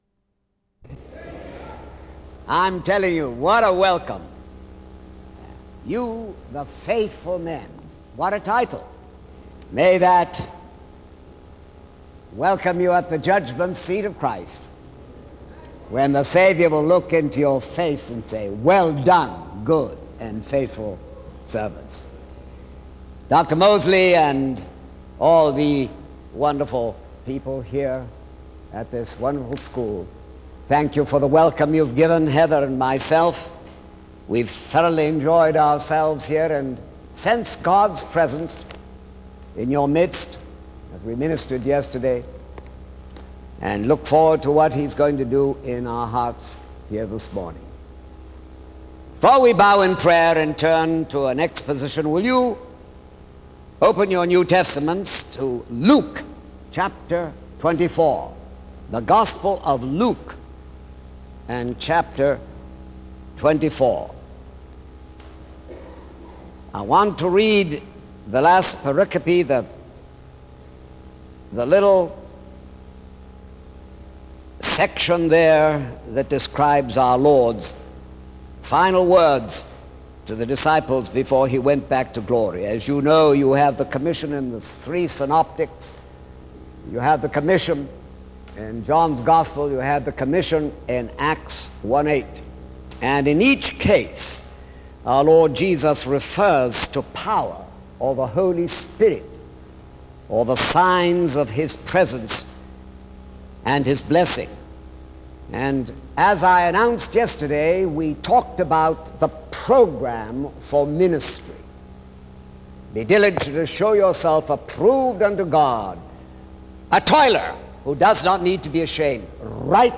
SEBTS Chapel - Stephen Olford November 13, 2003
In Collection: SEBTS Chapel and Special Event Recordings - 2000s Thumbnail Titolo Data caricata Visibilità Azioni SEBTS_Chapel_Stephen_Olford_2003-11-13.wav 2026-02-12 Scaricare